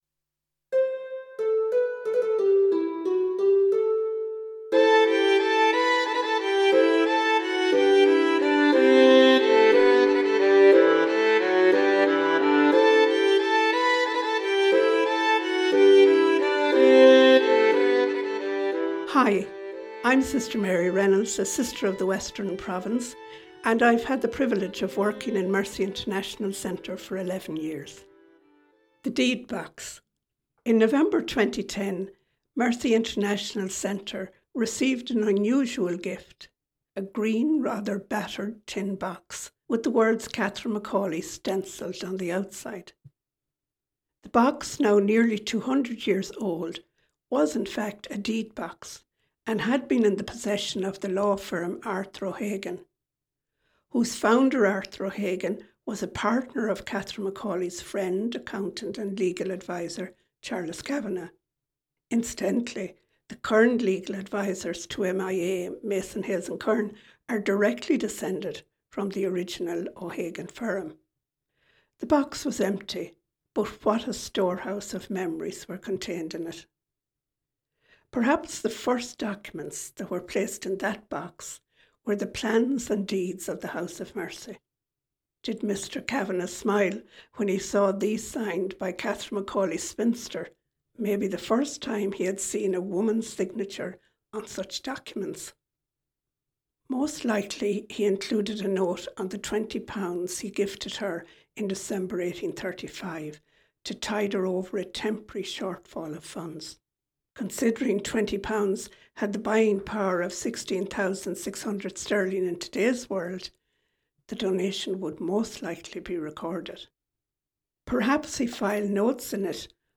On Mercy Day this year Mercy International Association has produced a variety of sound stories to celebrate our rich and fascinating heritage.